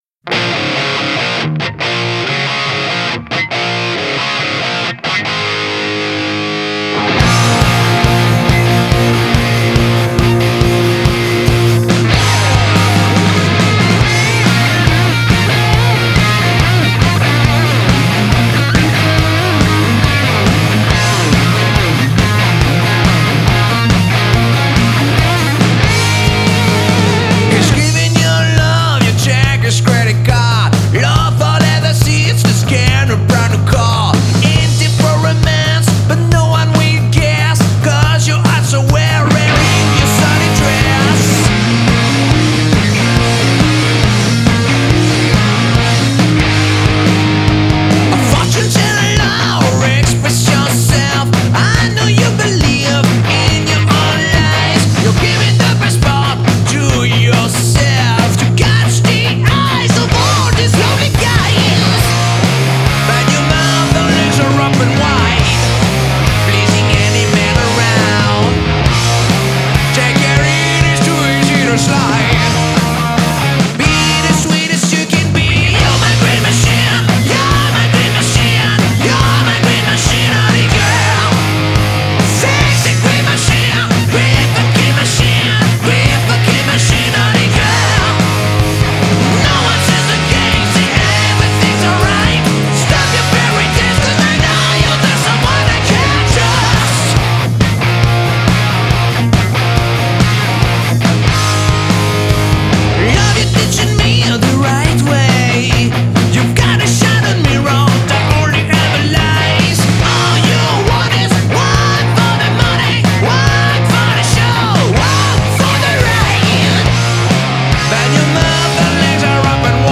hard'n roll France